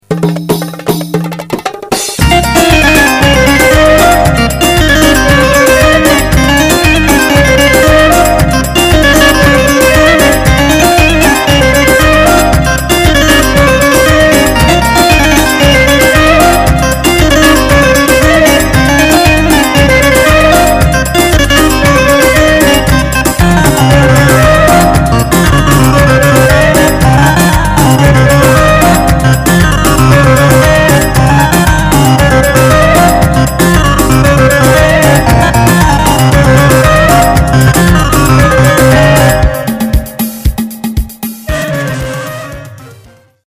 Yeni - Roland G6oo - Kolbastı Hoptek
Güzel alet yaa şu g600 seviyorm ama birazda işte şu kitleri ah bir kuvvetli dolgun olsa drumlar felan ziller güzelde...neyse dinleyelim G600 farkı...